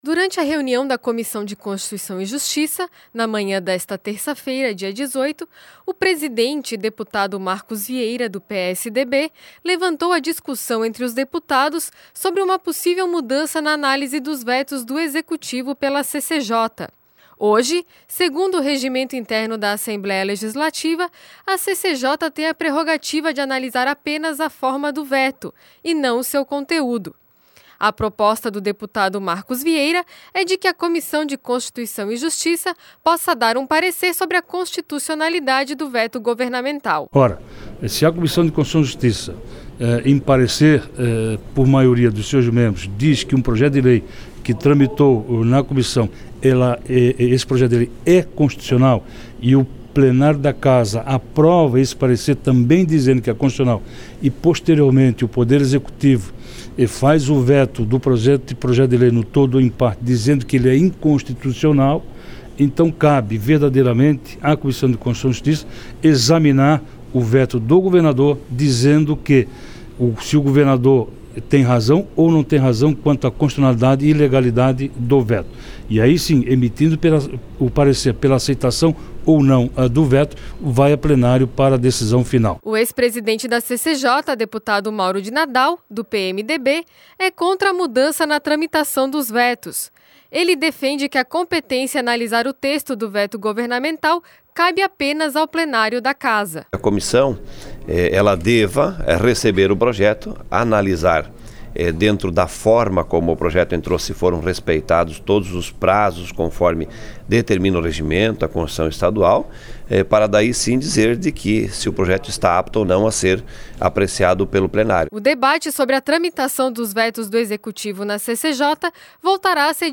Entrevistas: Deputado Marcos Vieira (PSDB); Deputado Mauro de Nadal(PMDB).